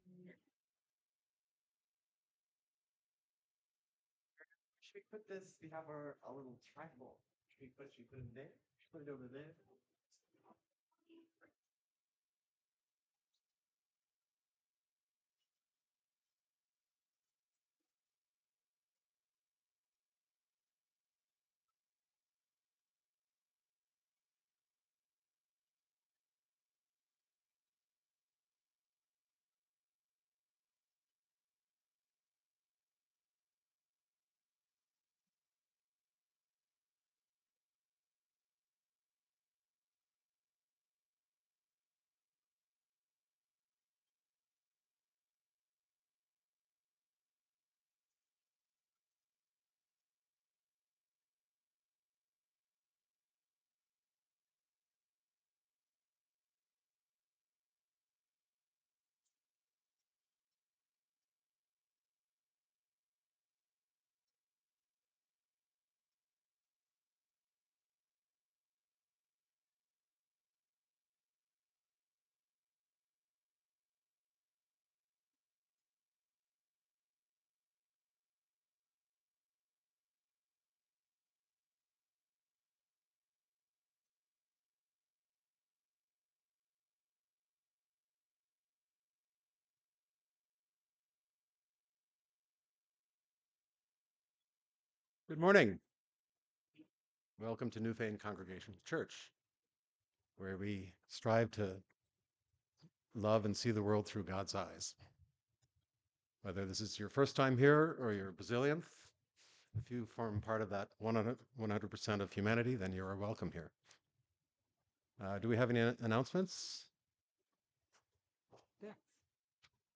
March 30, 2025 Service Digital Bulletin 03.30.25 – Lent 4